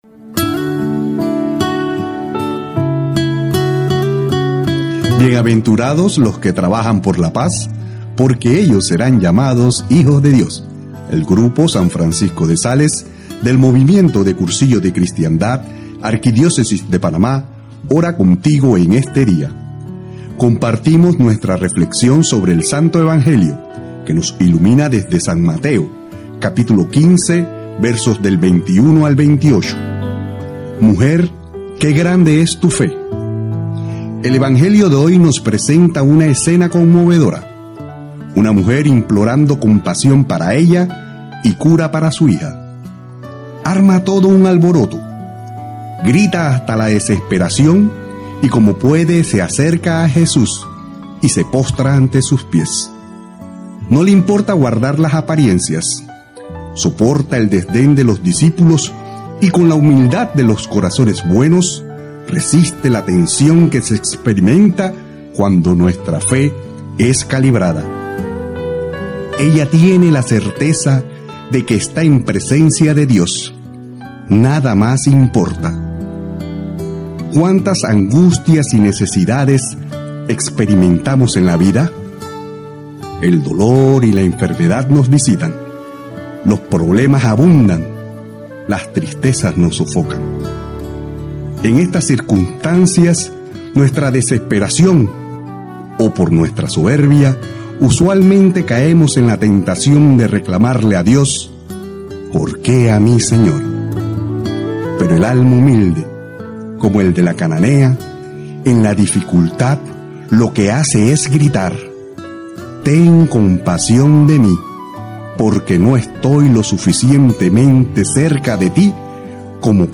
A continuación la audioreflexión preparada por el grupo «San Francisco de Sales» del Movimiento de Cursillos de Cristiandad de la Arquidiócesis de Panamá, junto a una imagen para ayudarte en la contemplación.